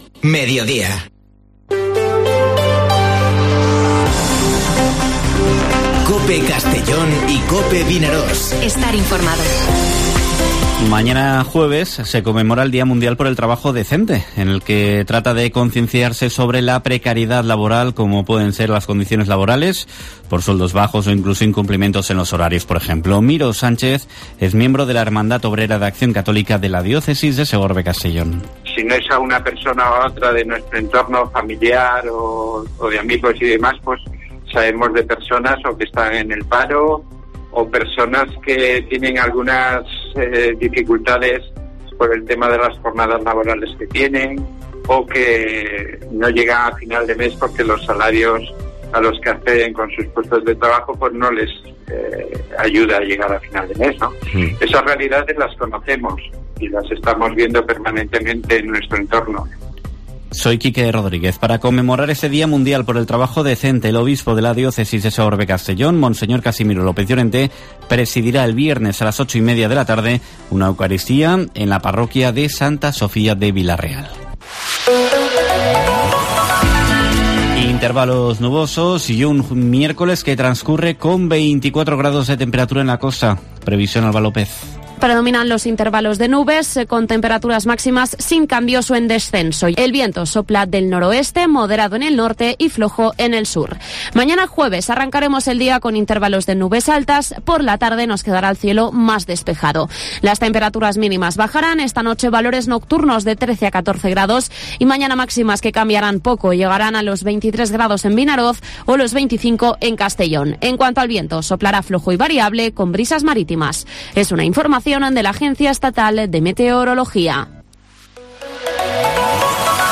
Informativo Mediodía COPE en la provincia de Castellón (06/10/2021)